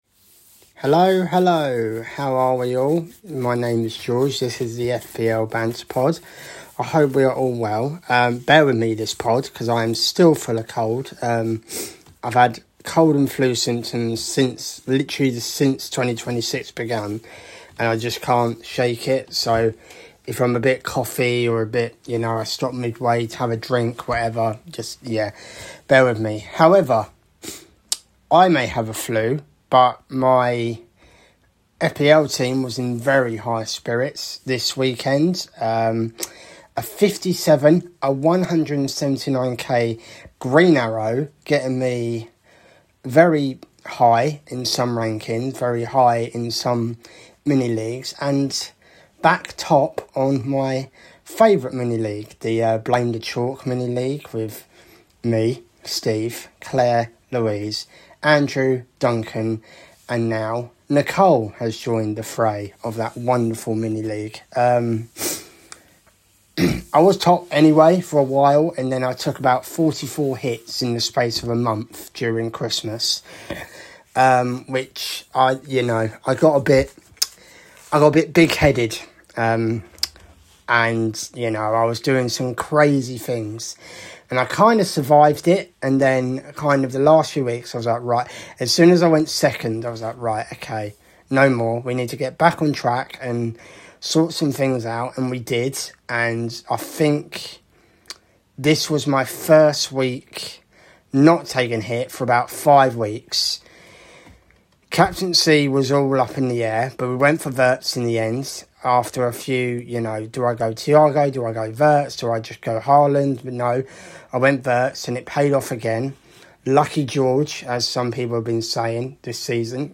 Hello all full of cold in this pod so I apologise.